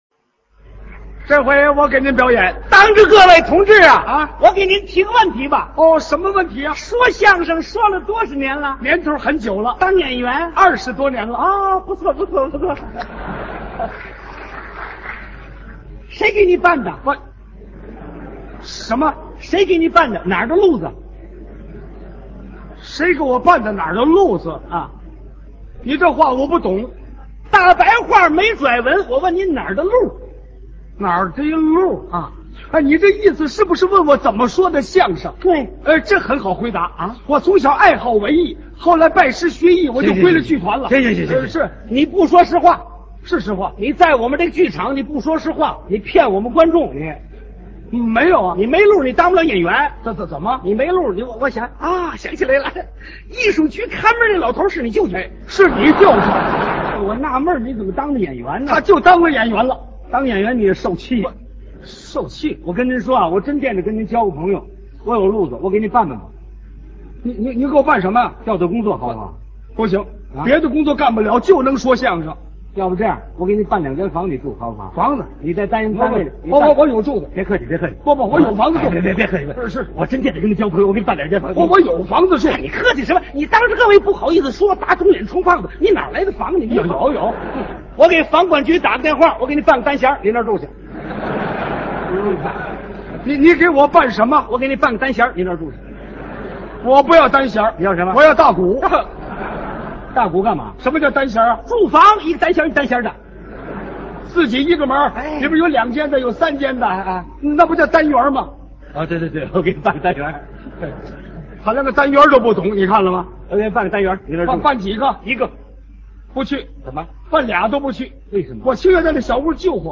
[25/2/2016]高英培相声不正之风